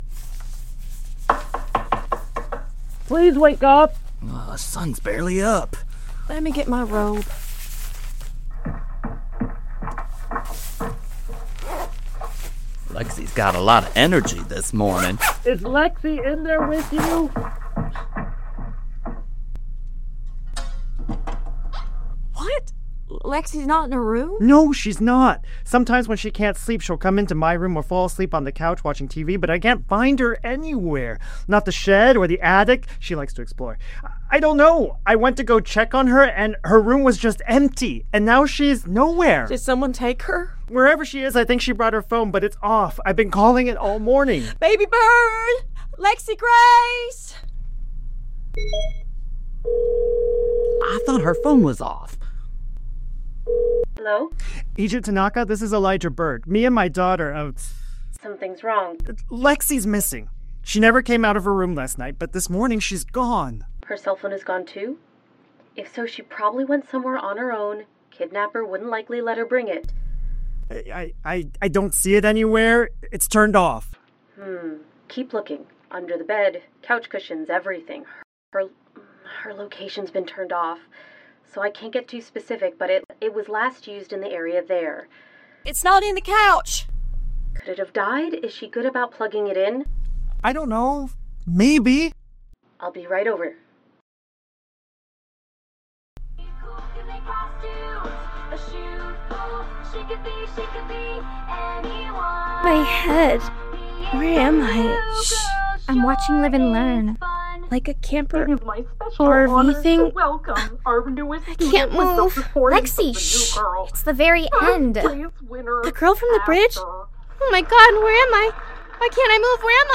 Each episode blends immersive audio drama with original songs containing clues.